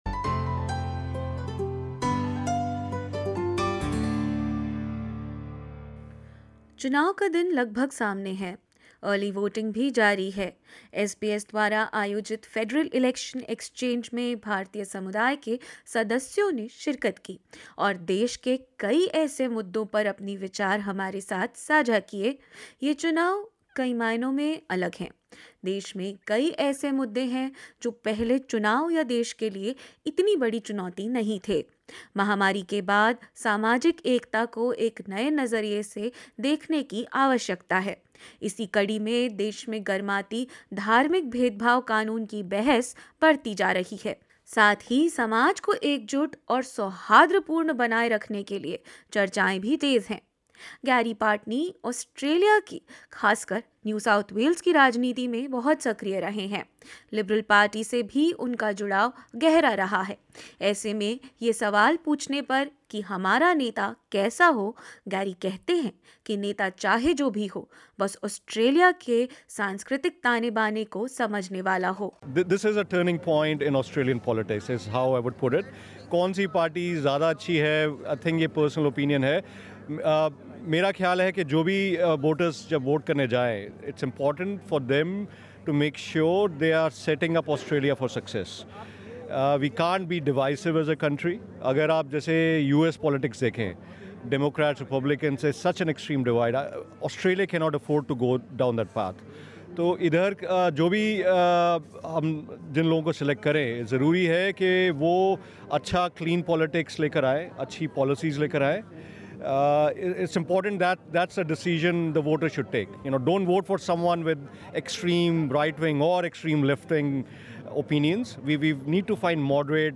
SBS Election Exchange provided a forum for Indian Australian voters to voice their opinions on a range of issues. In this last episode of the five part series, Indian Australian community shares its concerns on religious discrimination and cultural cohesiveness.